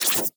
Cybernetic Technology Affirmation 9.wav